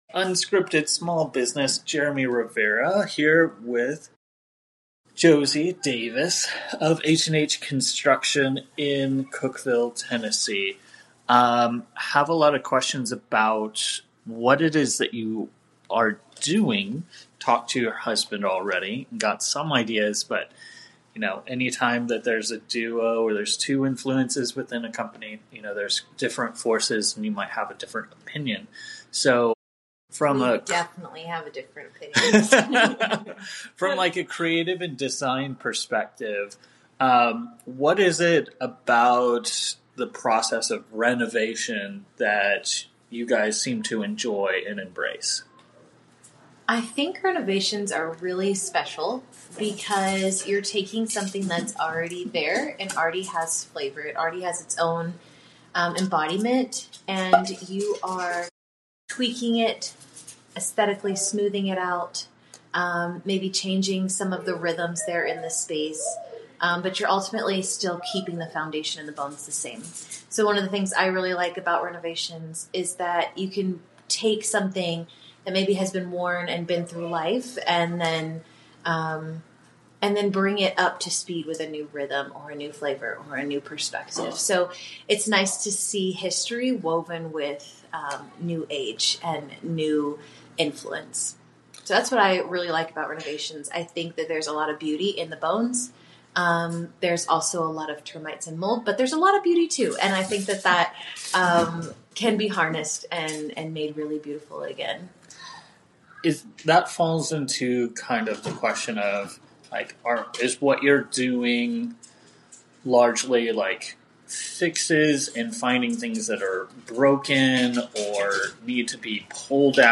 This two-part conversation explores how they've built a thriving renovation business focused on relationship over transactions, creativity over cookie-cutter solutions, and preserving the beauty in old homes while bringing them into the modern age.&nbsp